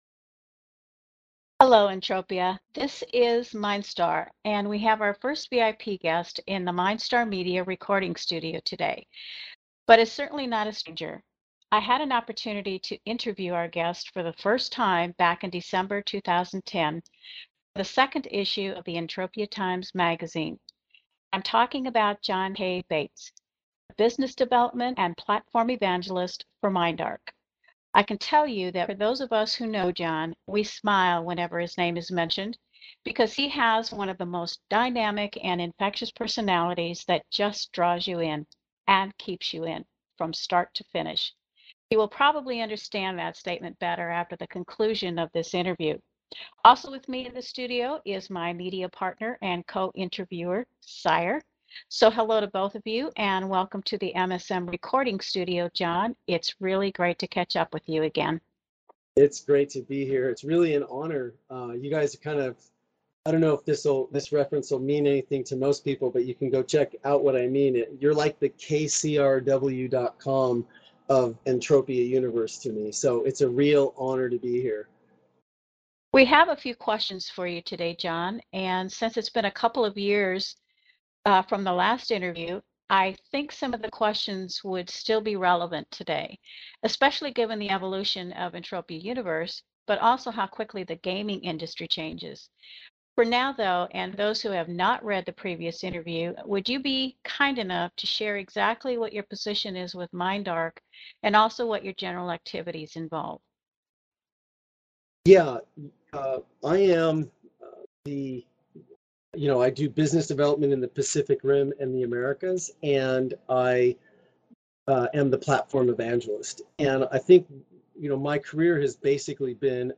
MindstarMedia audio interview